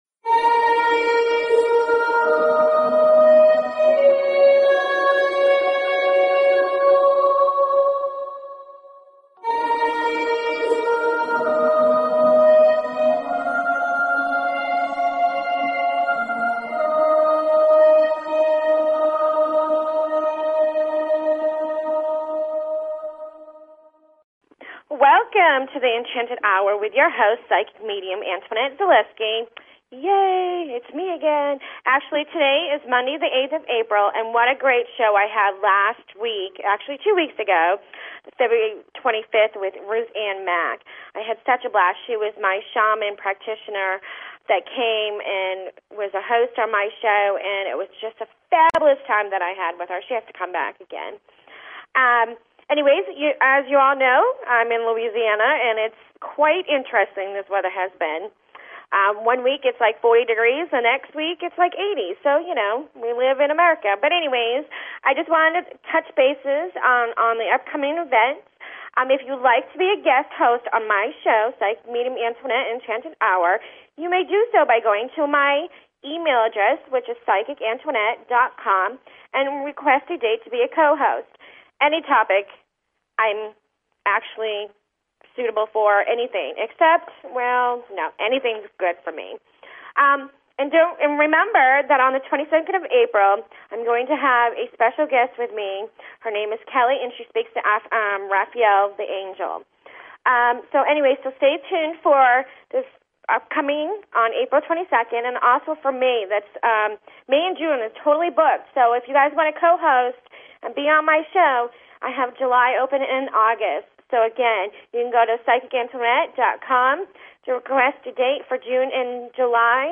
Talk Show Episode, Audio Podcast, Enlightenment_Hour and Courtesy of BBS Radio on , show guests , about , categorized as